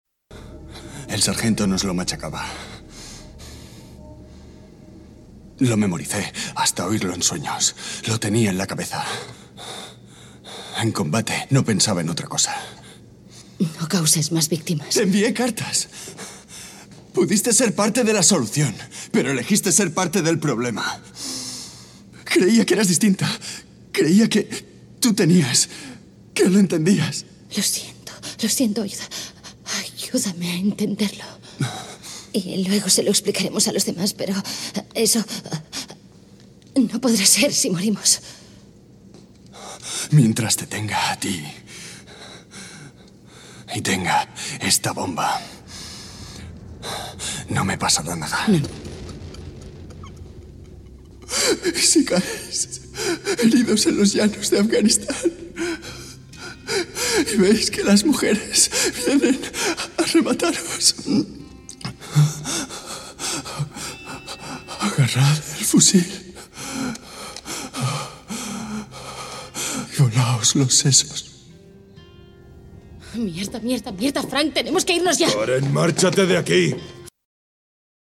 Spanish native actor (also Catalan) with professional experience in international studios with clients like Netflix, HBO, Cartoon Network, AXN, Calle 13, Films, Realitys With home-studio but acces to a professional one
kastilisch
Sprechprobe: Industrie (Muttersprache):